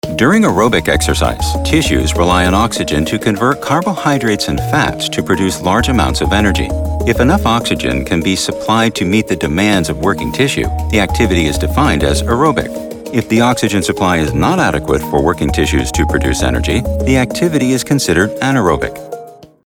Mature Adult, Adult
Has Own Studio
standard us
03_Excercise_spot.mp3